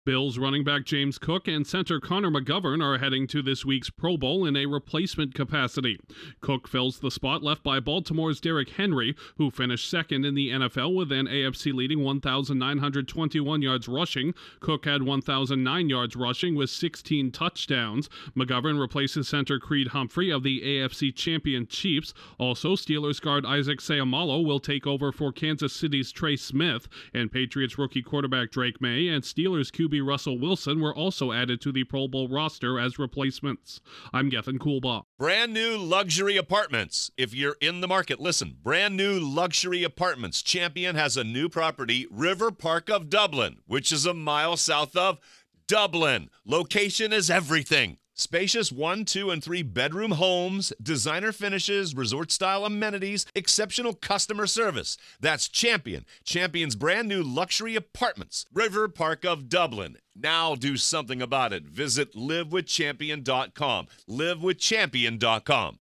A star running back and two notable quarterbacks are among the NFL Pro Bowl roster replacements. Correspondent